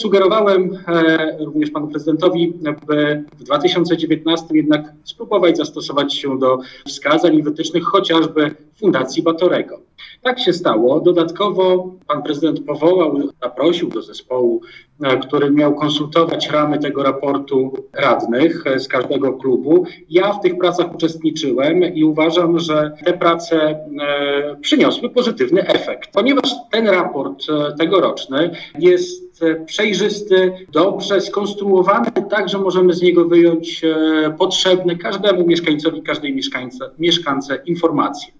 Formułę raportu o stanie miasta chwalił radny Hubert Górski.